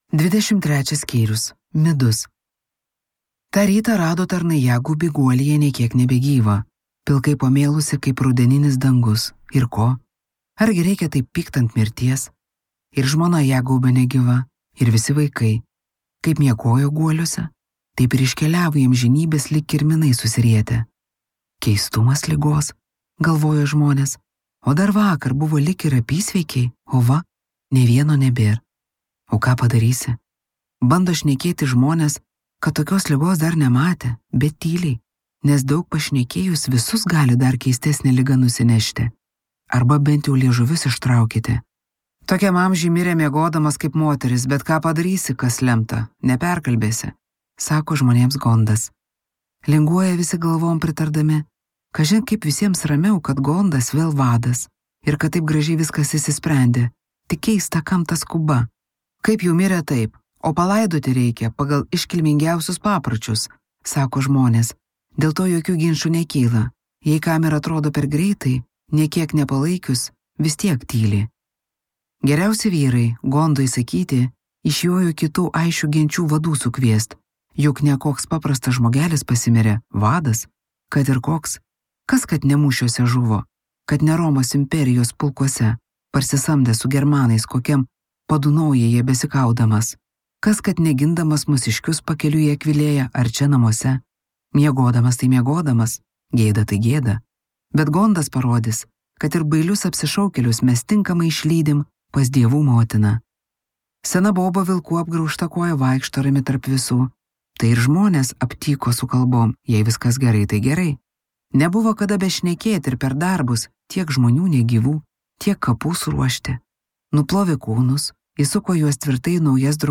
Glesum | Audioknygos | baltos lankos